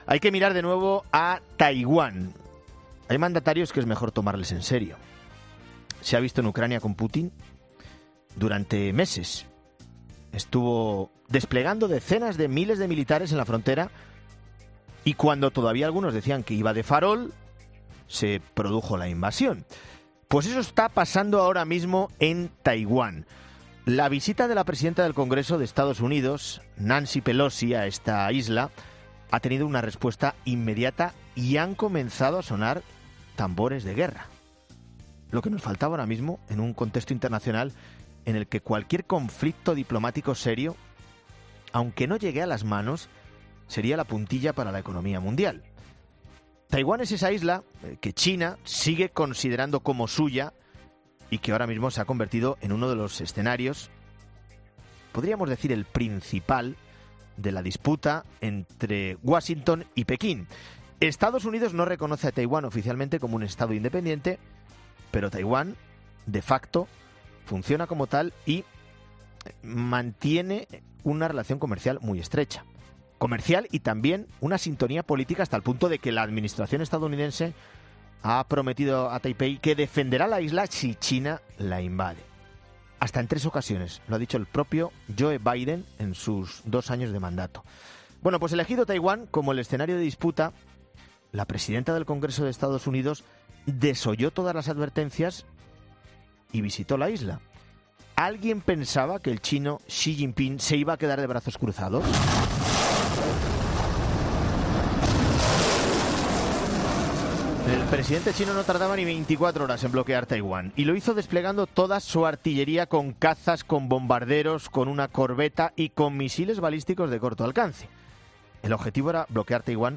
presentador de 'Herrera en COPE'